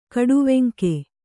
♪ kaḍuveŋke